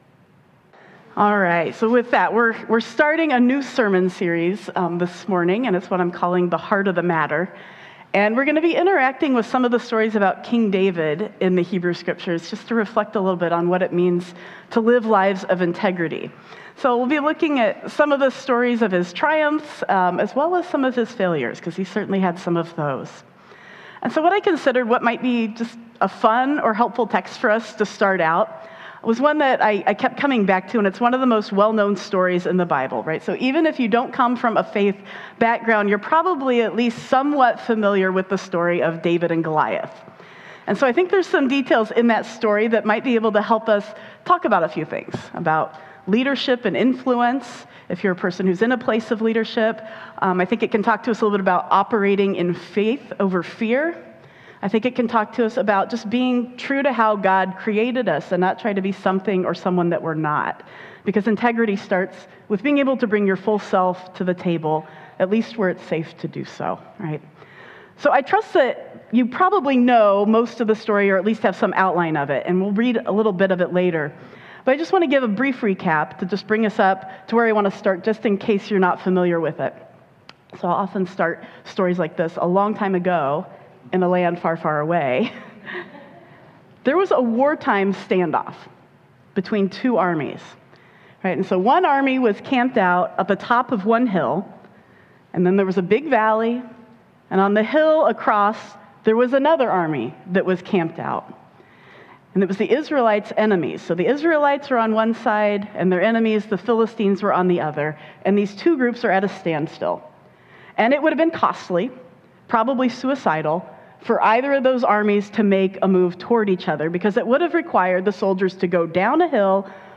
This morning we begin a new sermon series called “The Heart of the Matter” where we’re interacting with some of the stories about King David in the Hebrew Scriptures in order to reflect on what it means to live lives of integrity. This morning’s story is about David and Goliath—what it means to be true to who you are and live courageous lives.